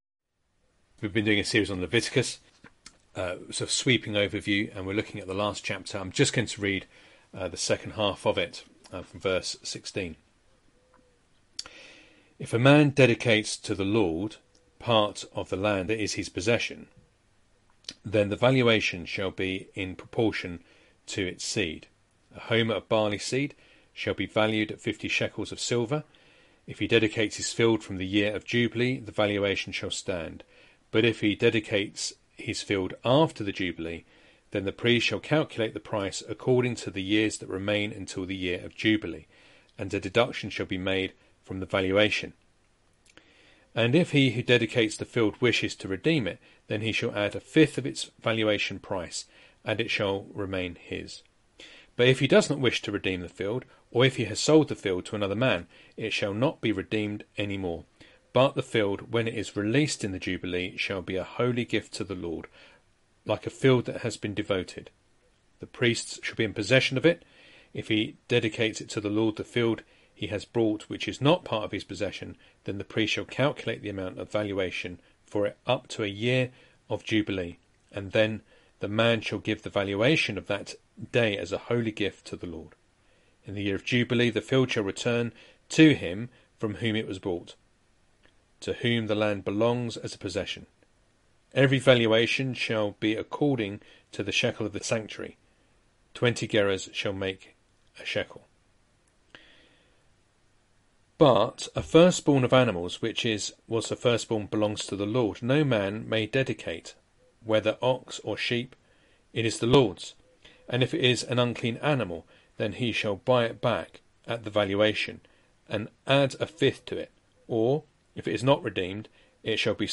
Matthew 5:33-37 Service Type: Sunday Morning Reading and Sermon Audio